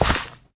gravel2.ogg